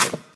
step-2.ogg.mp3